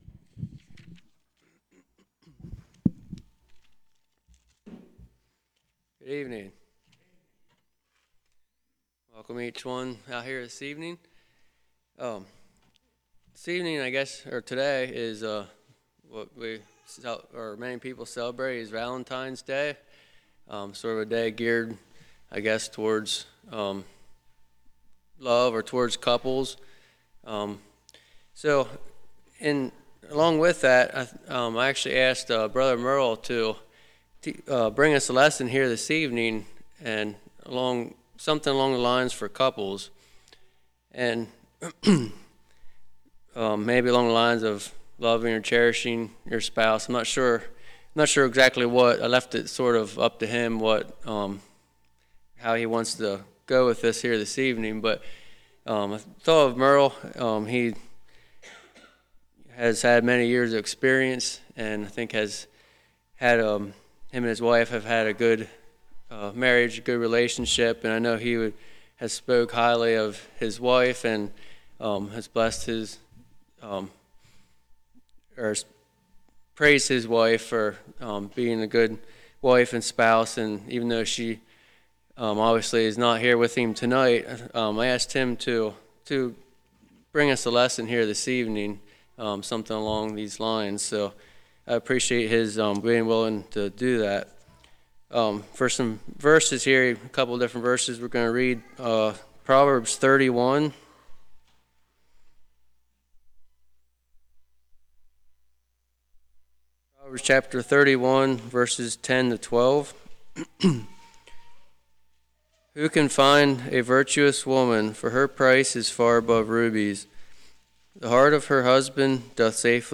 Service Type: Prayer Meeting